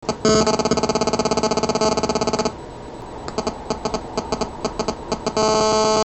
This sounds very much like interference from a cellular phone switching between cells (e.g. when driving through a tunnel) or receiving a call or message.
This sounds a LOT like inducted interference from a GSM mobile.
1. first part – sounding like a buzzy tone
2. dit ditdit, dit ditdit part
These timeslots happen to be spaced 4.615 ms apart, yielding a signal envelope which looks a lot like a dirty 217 Hz square wave.
The fact that there is complete silence in the middle of the clip says that the phone stopped transmitting for a short period, this could be either because the clip captured two separate air interface procedures (i.e. the end of a voice call followed by a location update or an SMS or something) or that it was a recording of a GPRS session where the phone was not transmitting any data during the silence period.